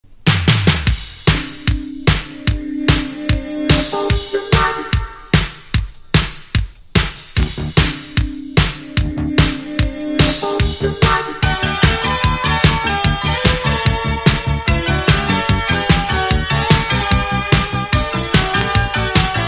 威勢の良い爽快ポップナンバーです。